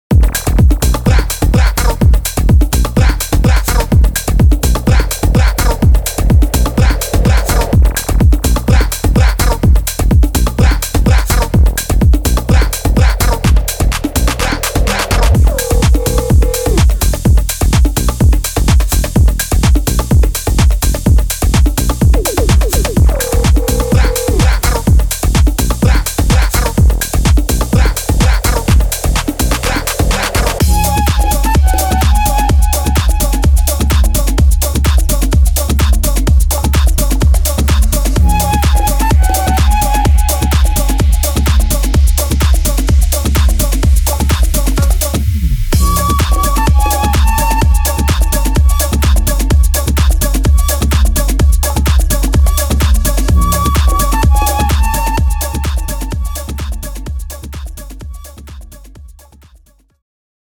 Latin American